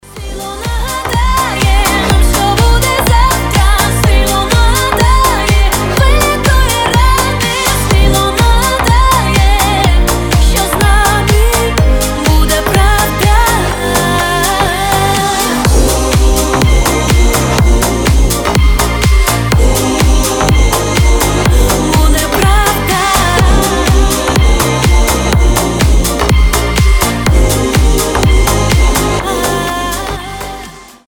• Качество: 320, Stereo
громкие
украинские
ремиксы